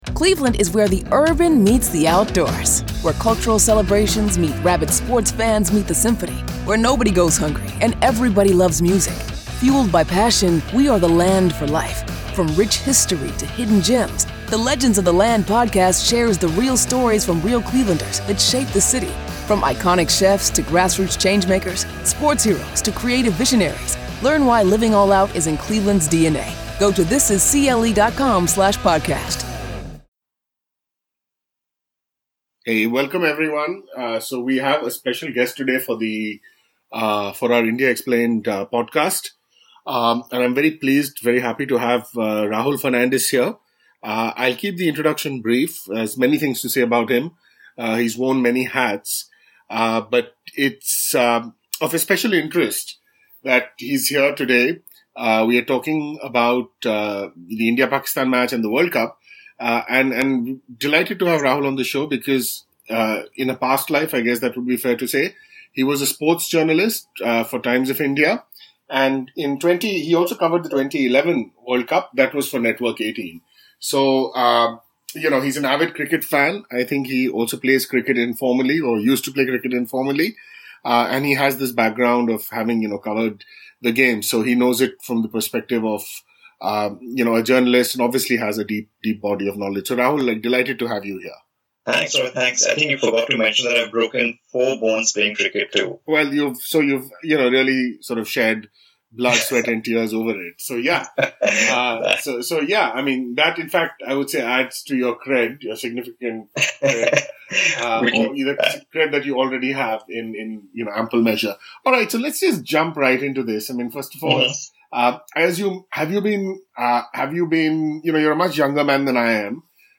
A long discussion